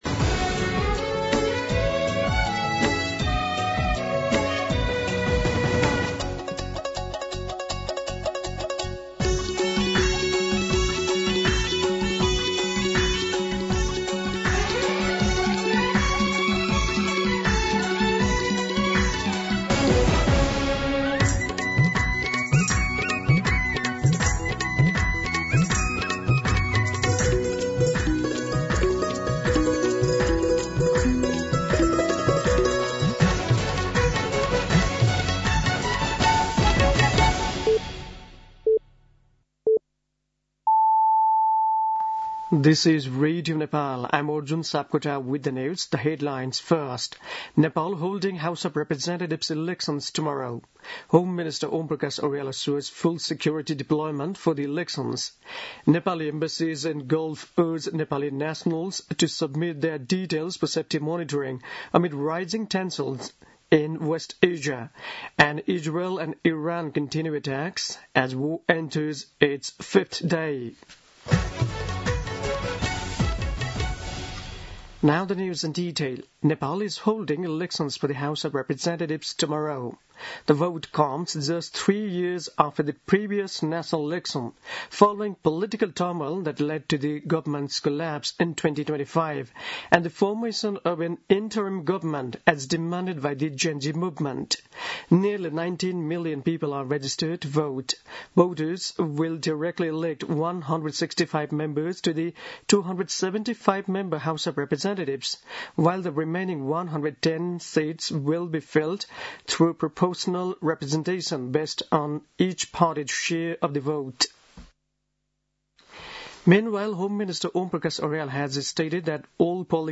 दिउँसो २ बजेको अङ्ग्रेजी समाचार : २० फागुन , २०८२